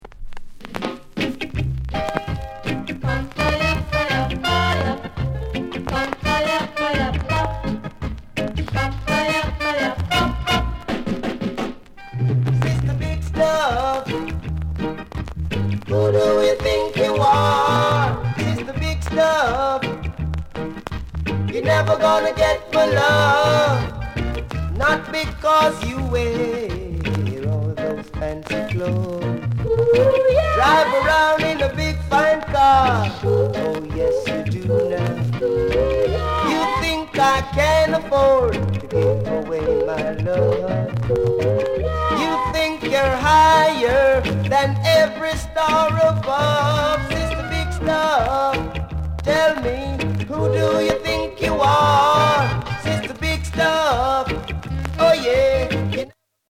BEAUTIFUL INST